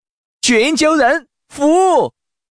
Index of /mahjong_xianlai/update/12943/res/sfx/changsha_man/